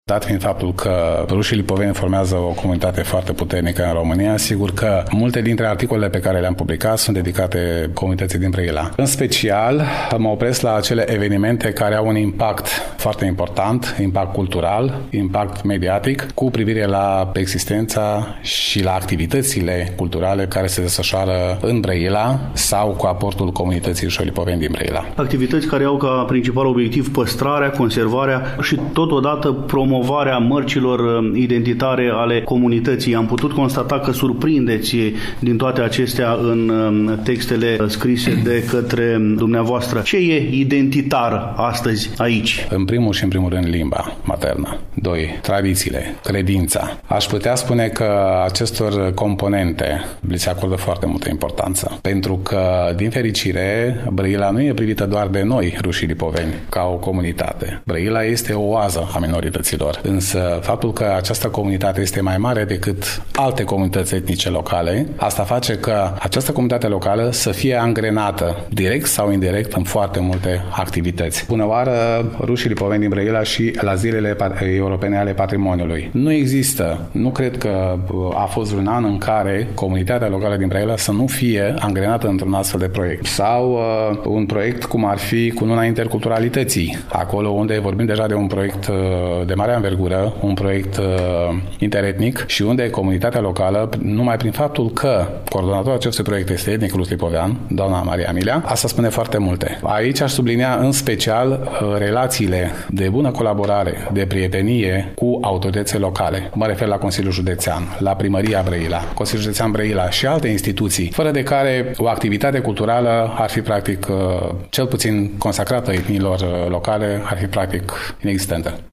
În emisiunea de astăzi poposim acasă la rușii lipoveni din Brăila, mai exact în incinta sediului cultural al Comunității din cartierul Pisc, de pe strada Alexandru Davila, Numărul 13.